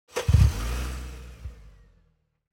دانلود آهنگ ماشین 29 از افکت صوتی حمل و نقل
جلوه های صوتی
دانلود صدای ماشین 29 از ساعد نیوز با لینک مستقیم و کیفیت بالا